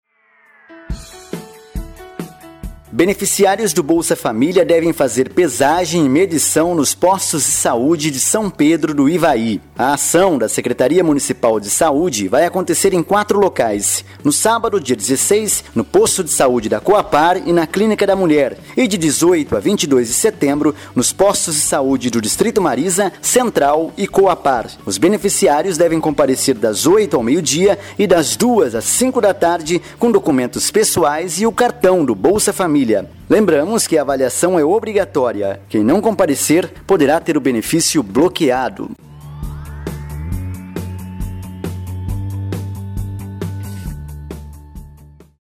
Ou�a Spot de r�dio sobre a pesagem